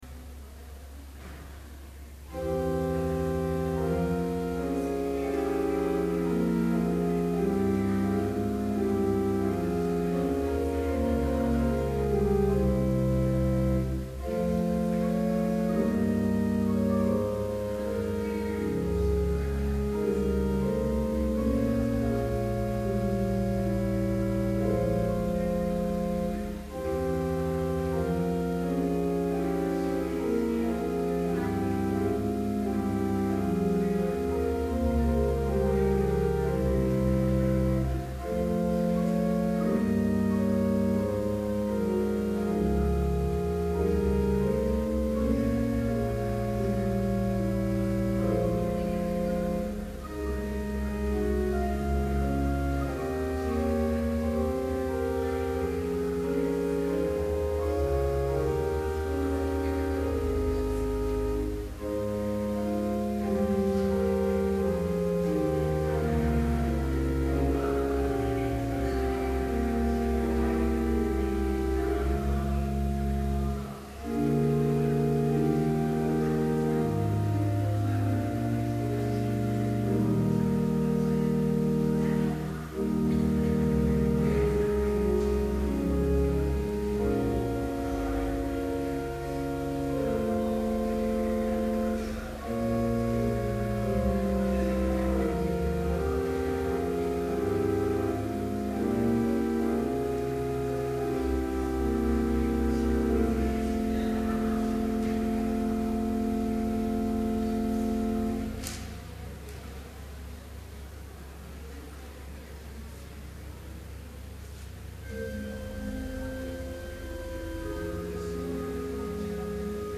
Chapel worship service held on February 7, 2012, BLC Trinity Chapel, Mankato, Minnesota
Complete service audio for Chapel - February 7, 2012